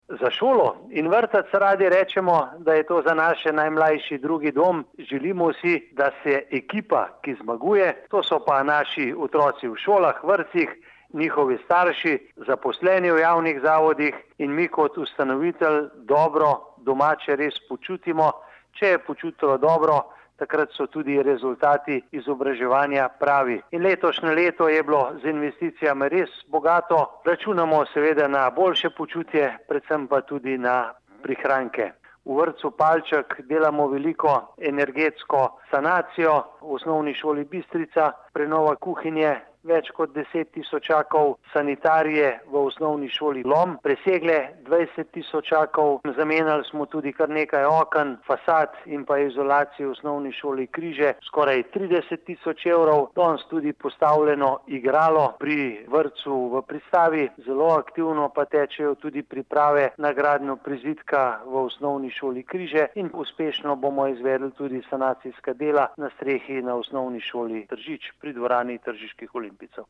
izjava_mag.borutsajoviczupanobcinetrzic_prvisolskidan.mp3 (1,7MB)